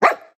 Minecraft Version Minecraft Version latest Latest Release | Latest Snapshot latest / assets / minecraft / sounds / mob / wolf / cute / bark2.ogg Compare With Compare With Latest Release | Latest Snapshot
bark2.ogg